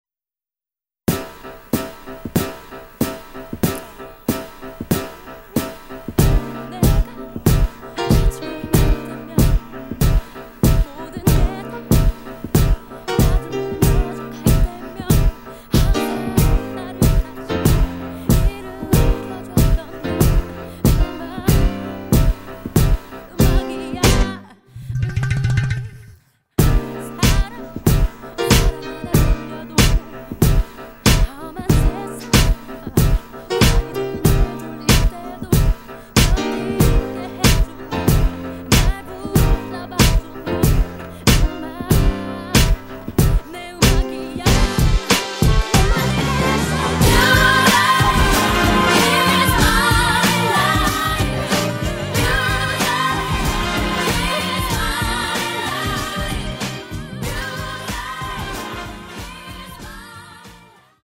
음정 원키 (코러스
장르 가요 구분